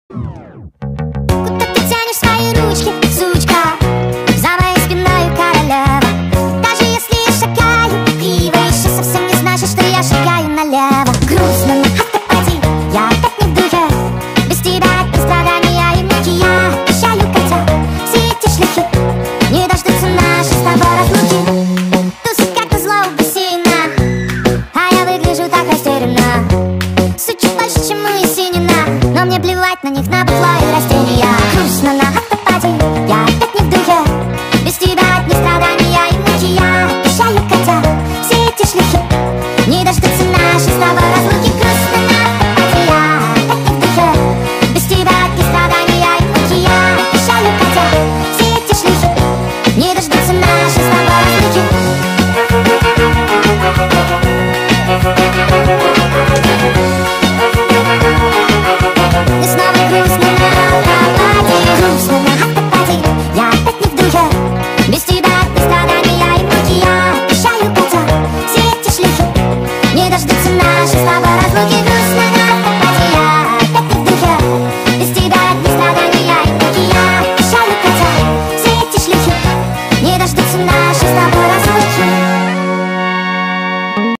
Жанр музыки: Поп